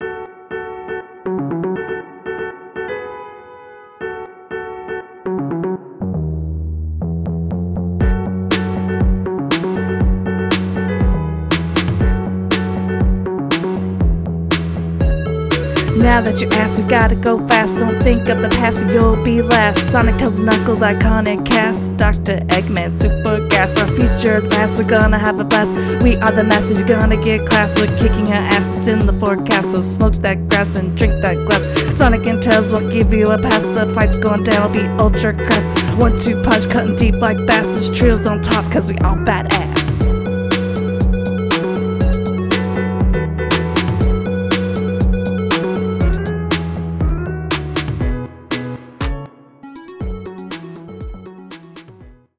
ep89-sonic-mania-rap.mp3